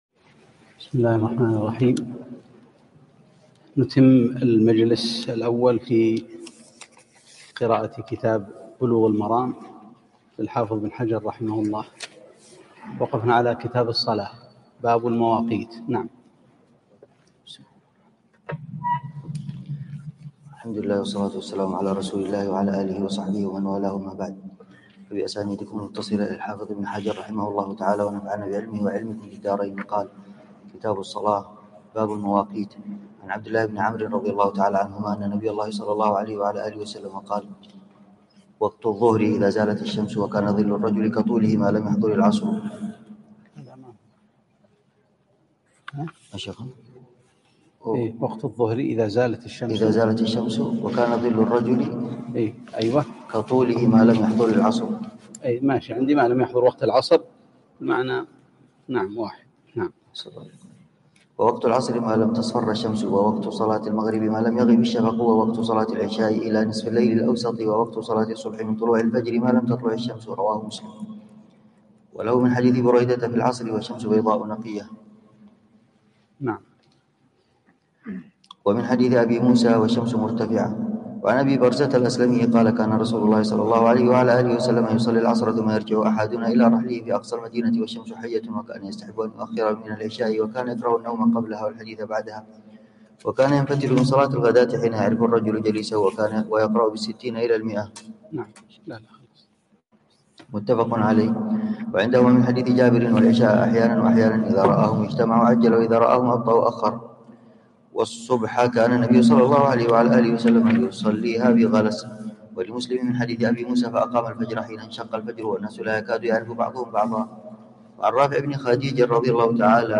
(٢) مجلس سماع وتعليق على كتاب بلوغ المرام - كتاب الصلاة